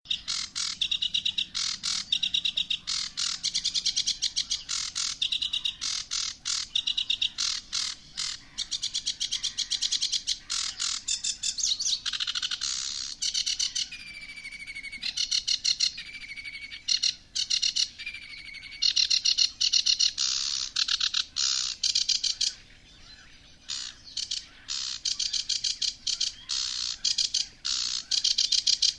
• Качество: 128, Stereo
птицы
переливы
Красивые голоса птиц